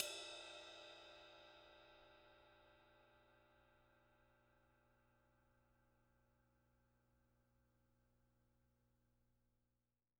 R_B Ride 01 - Close.wav